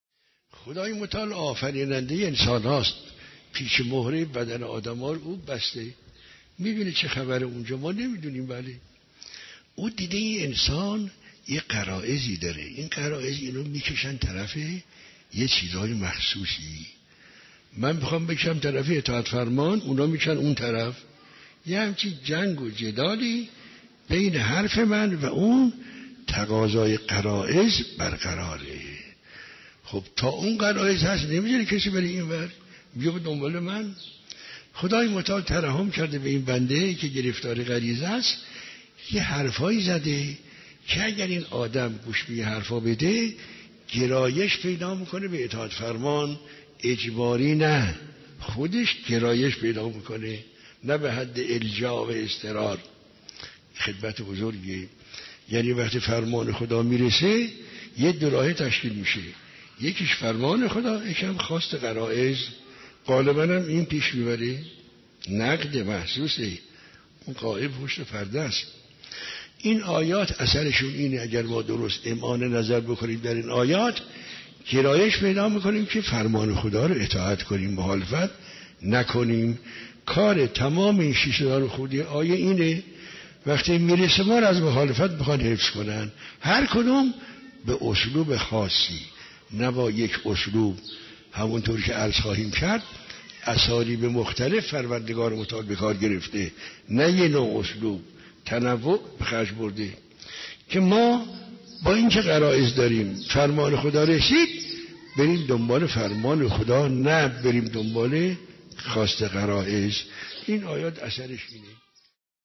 دروس اخلاق